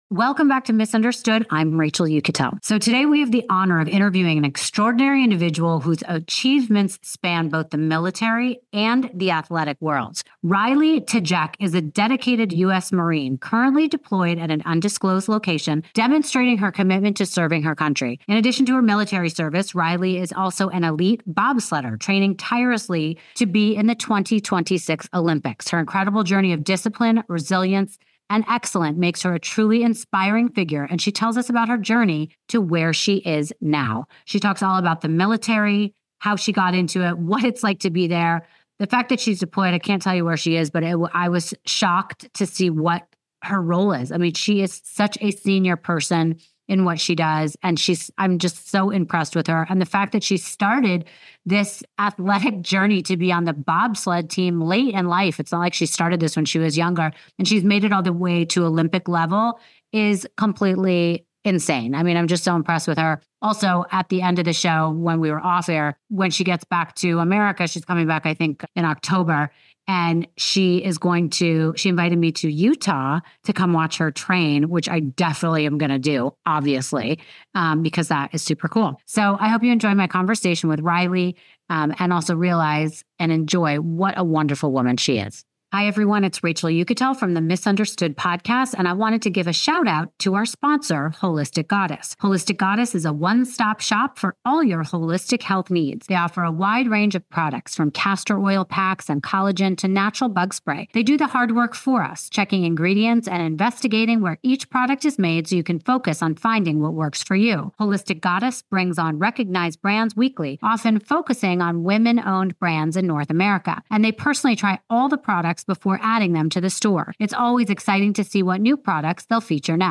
We have the honor of interviewing an extraordinary individual whose achievements span both the military and athletic worlds.